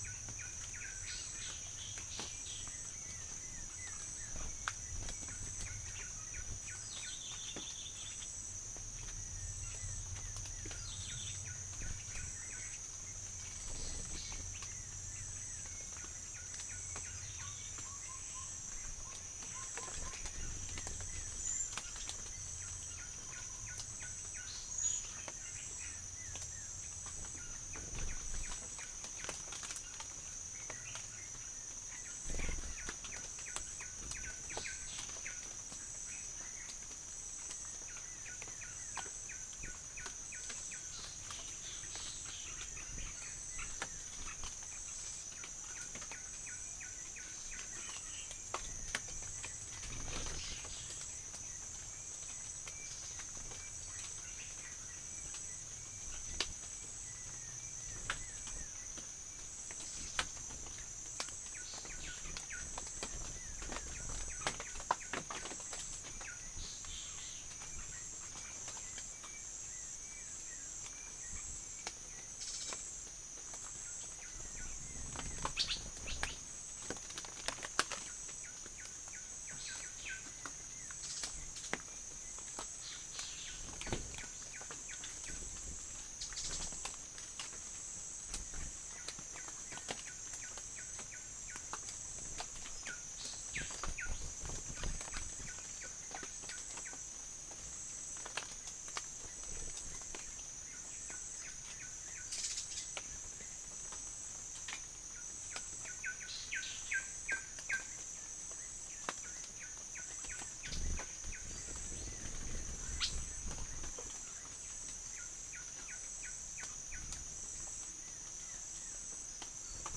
Berbak NP phase 1 and 2
Orthotomus sericeus
Micropternus brachyurus
Pelargopsis capensis
Dryocopus javensis
Rhipidura javanica
Halcyon smyrnensis
Prinia flaviventris
Mixornis gularis
Pycnonotus plumosus
Macronus ptilosus
Psittacula longicauda